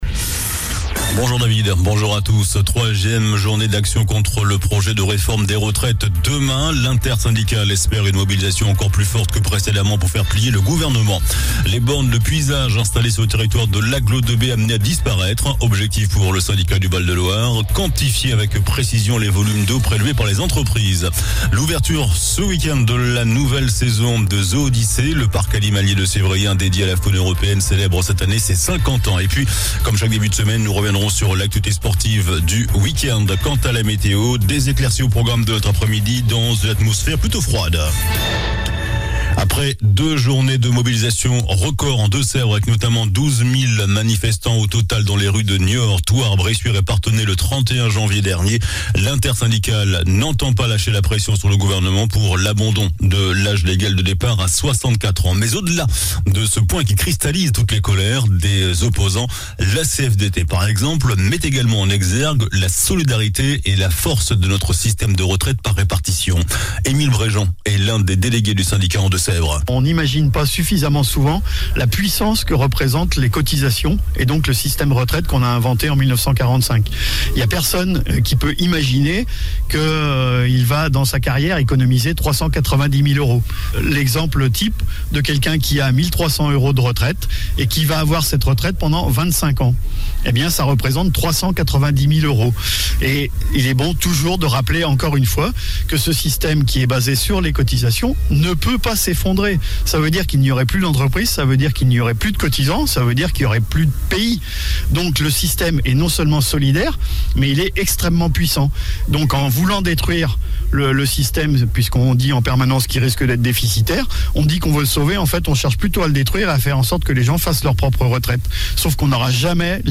JOURNAL DU LUNDI 06 FEVRIER ( MIDI )